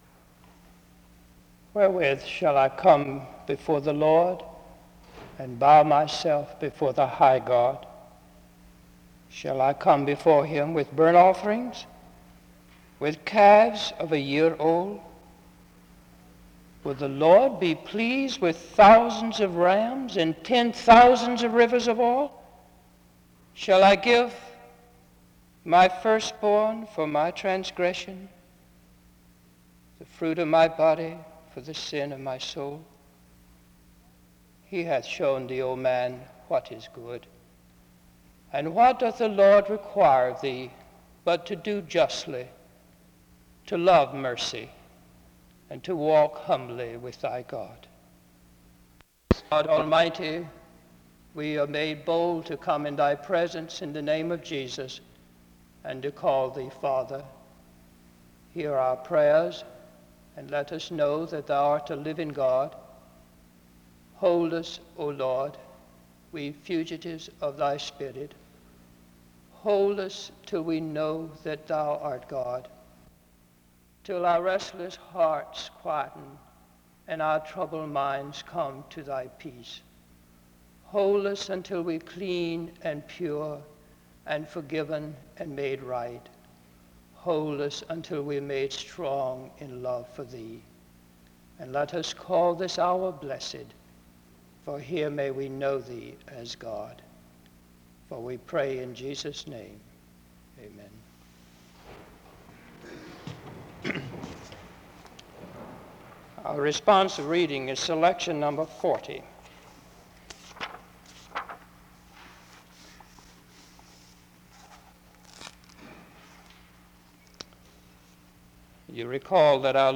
The service begins with a prayer and a responsive reading (0:00-3:41).
A portion of John chapter 4 is read, and another prayer is offered (3:42-10:12).